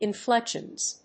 発音記号
• / ˌɪˈnflɛkʃʌnz(米国英語)
• / ˌɪˈnflekʃʌnz(英国英語)